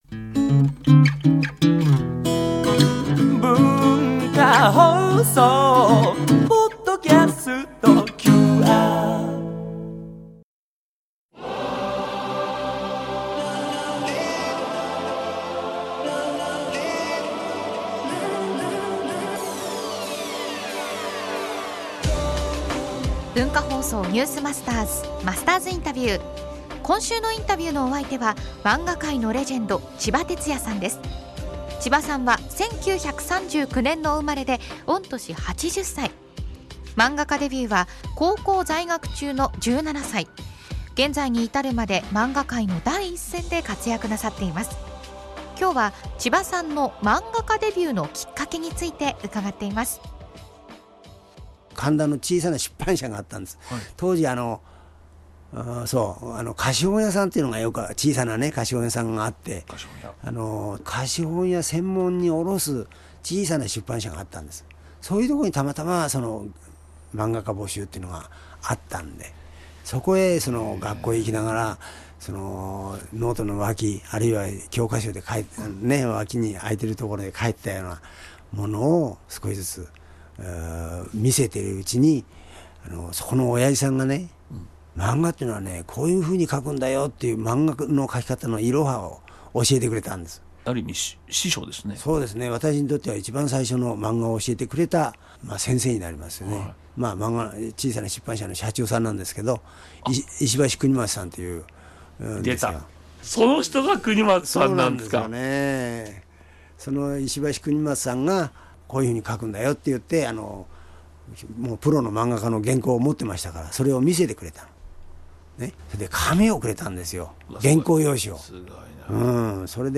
今週のインタビューのお相手は漫画界のレジェンド　ちばてつやさんです。
（月）～（金）AM7：00～9：00　文化放送にて生放送！